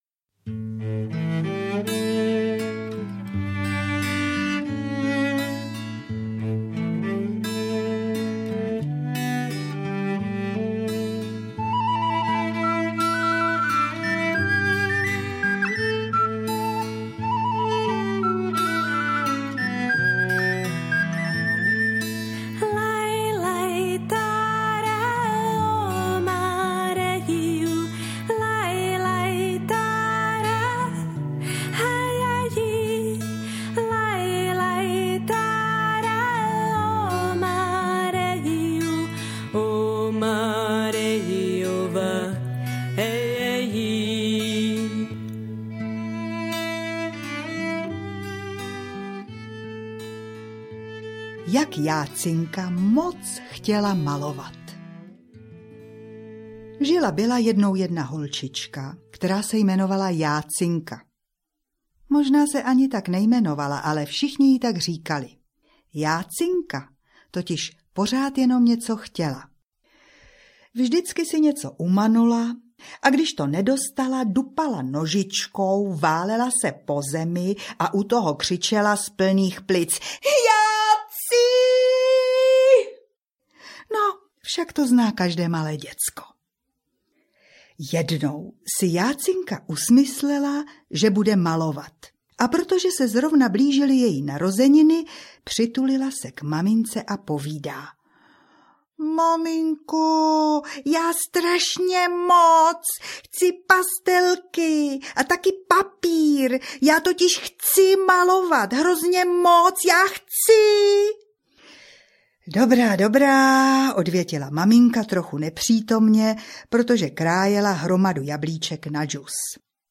Indigové pohádky audiokniha
Ukázka z knihy
„Audio Indigové pohádky je moc hezky namluveno, líbí se mi střídání mužských a ženských hlasů, kdy má každá pohádka svého vypravěče i originální hudbu...“Zatímco tištěná kniha nadchne barevnými ilustracemi od devíti různých ilustrátorek, namluvená audio kniha zaujme jemným zpracováním, kde se mísí mluvené slovo od pěti interpretů